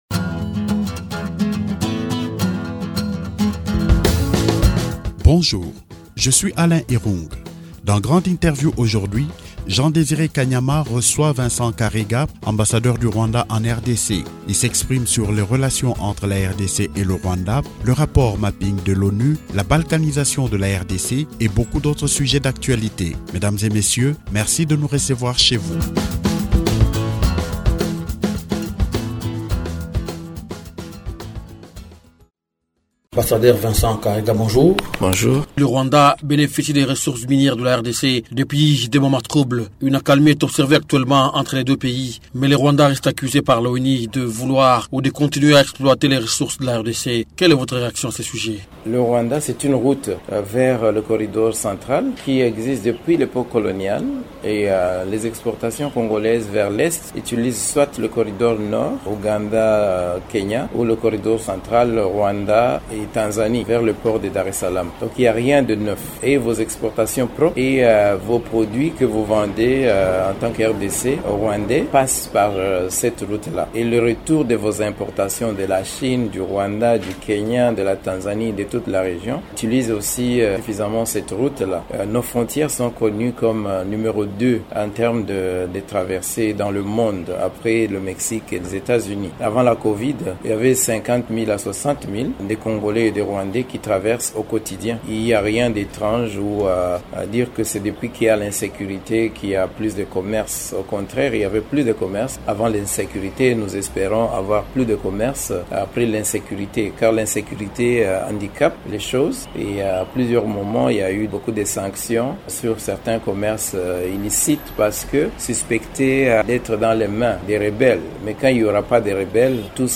Grande Interview reçoit aujourd’hui Vincent Karega, ambassadeur du Rwanda en RDC.